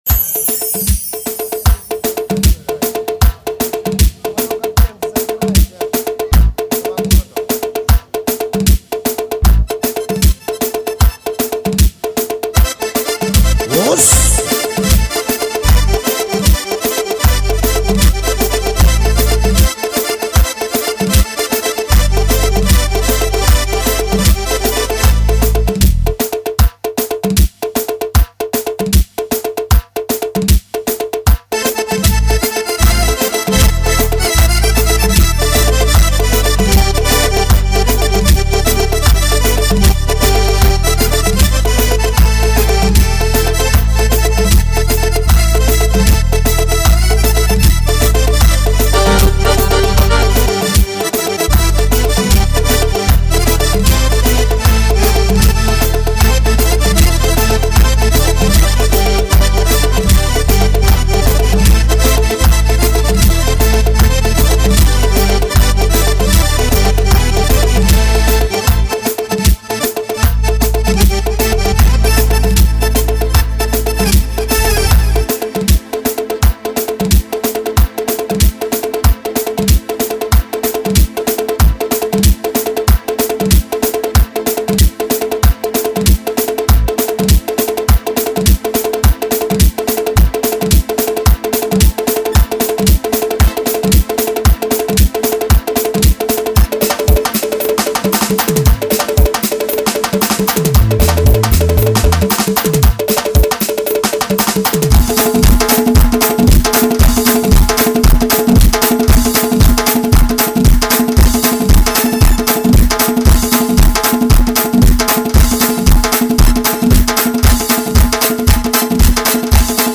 lezginka---gruzinskaya---samaya-nastoyaschaya-lezginka.mp3